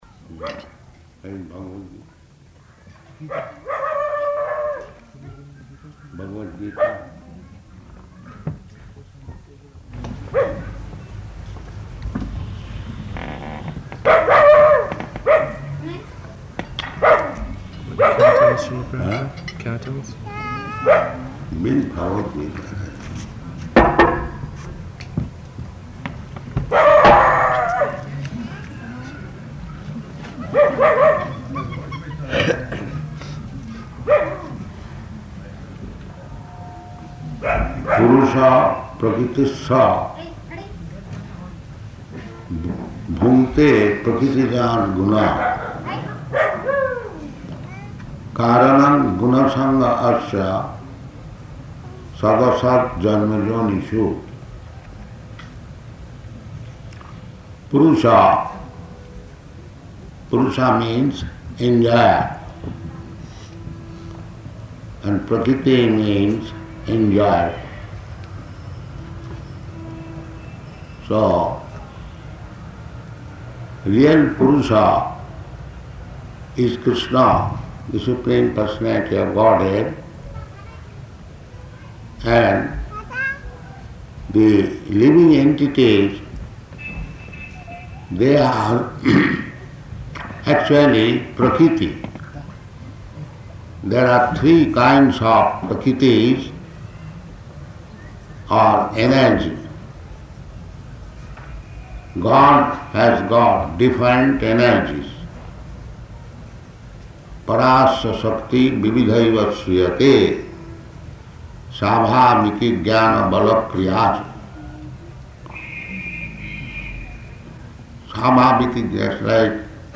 Bhagavad-gītā 13.22 --:-- --:-- Type: Bhagavad-gita Dated: August 17th 1976 Location: Hyderabad Audio file: 760817BG.HYD.mp3 Prabhupāda: Where is Bhagavad-gītā?